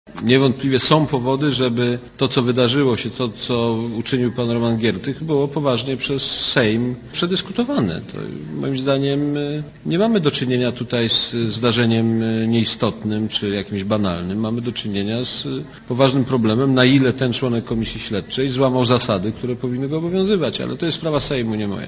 * Mówi Aleksander Kwaśniewski*
Niewątpliwie są powody, by to, co się wydarzyło, co uczynił pan Roman Giertych, było przez Sejm przedyskutowane - powiedział prezydent na czwartkowej konferencji prasowej.